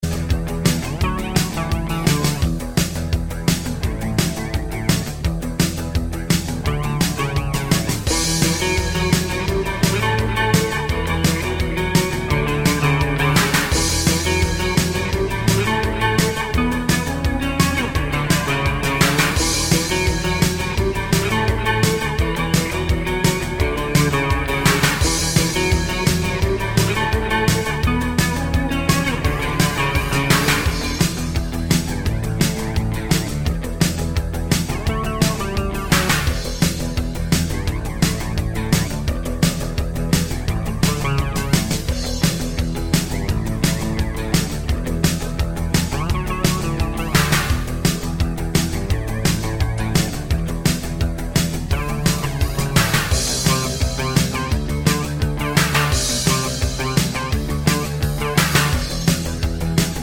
no Backing Vocals Indie / Alternative 3:36 Buy £1.50